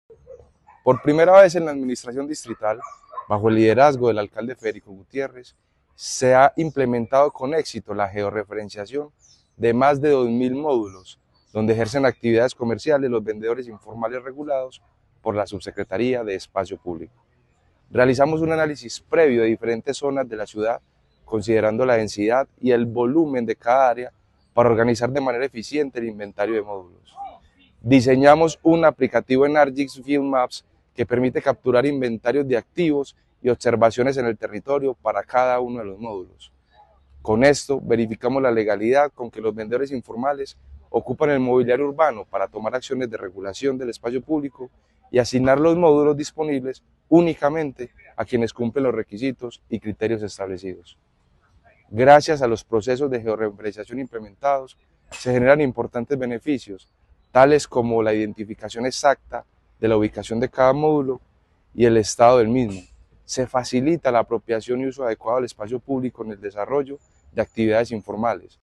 Declaraciones-subsecretario-de-Espacio-Publico-David-Ramirez.mp3